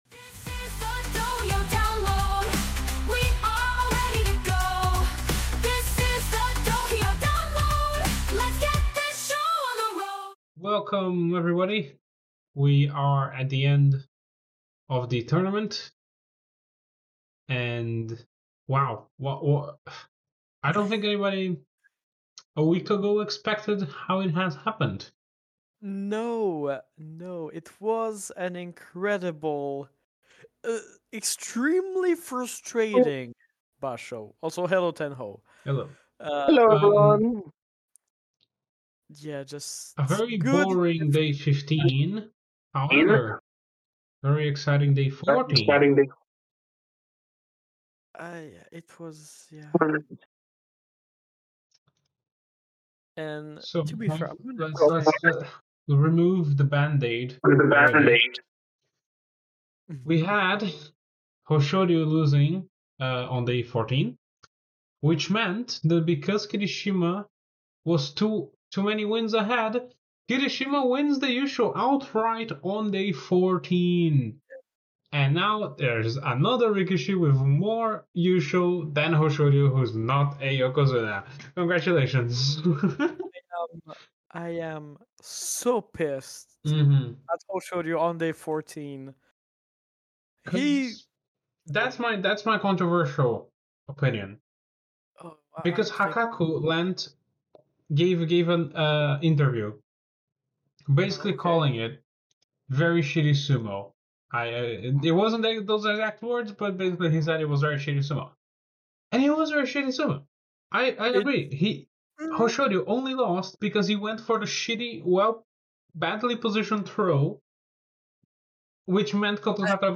a deep conversation about Sumo’s leadership and responsibility of the top-rankers, in which everyone chimes in to create a lively discussion